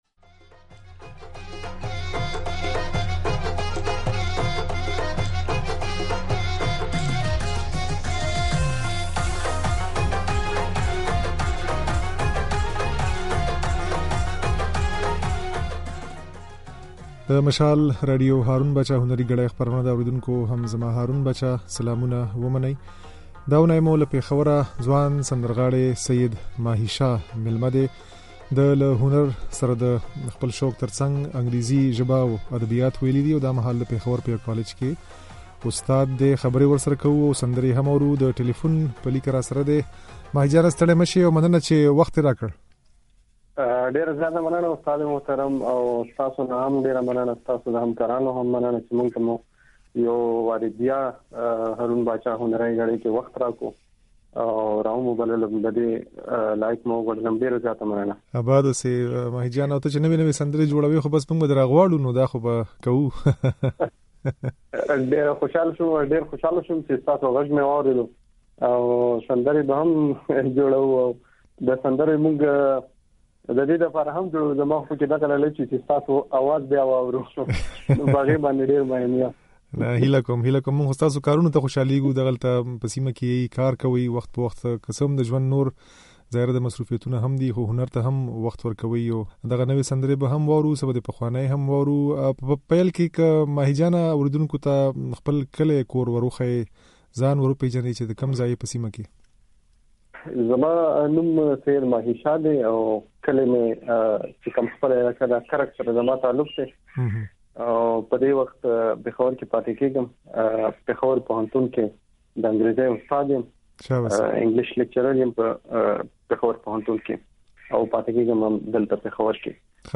ځينې سندرې يې د غږ په ځای کې اورېدای شئ.